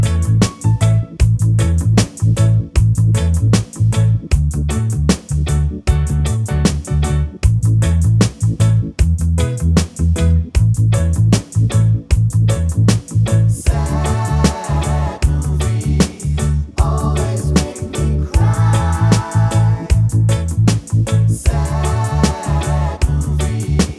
no Backing Vocals Reggae 3:57 Buy £1.50